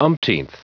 Prononciation du mot umpteenth en anglais (fichier audio)
umpteenth.wav